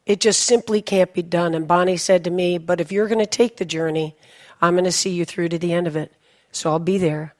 Now, let’s have some examples of can’t  in American English: